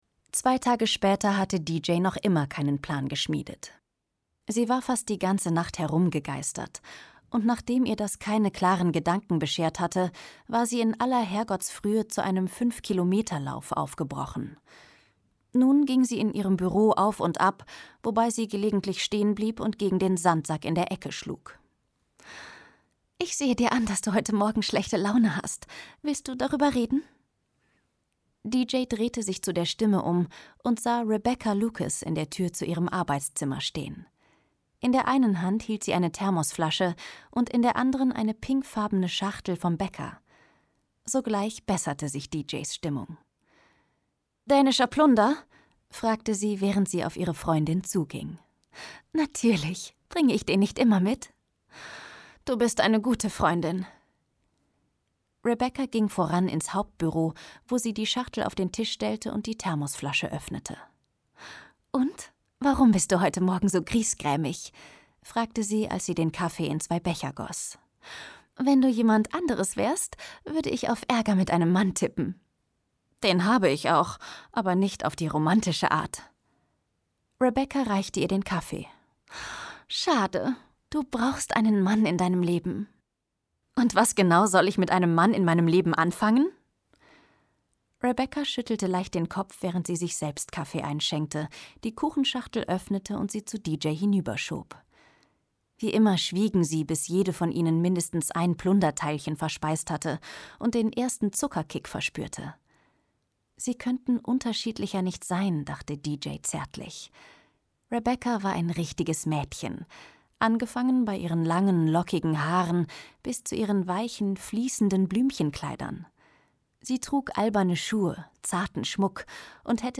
Jahrhundert • Anastasia Steele • Belletristik: Erotik • Belletristik: Romanzen (romance) • Bridget Jones • Chick Lit • chick lit deutsch • Drama • Ekstase • EL James • Erotischer Liebesroman • erotischer Roman • Frauen Bücher • Frauen Bücher Bestseller • Frauenroman • Frauenroman Bestseller • Freche Frauen • Gefühl • Gefühle • grey • Hörbuch; Literaturlesung • Humor • Kerstin Gier • Leidenschaft • Liebe • Liebe / Beziehung • Liebeskummer • Liebeskummer; Romane/Erzählungen • Liebesleben • Liebesroman • Liebesromane • Liebesromane für Frauen • Liebesroman (modern) • Mr. Right • Passion • Romantic Comedy • Romantik • Sehnsucht • Sex • Shades • Shades of Grey • Steele • Tragik • Traummann • Unterhaltung • USA • USA; Romane/Erzählungen • Verführung • Verlangen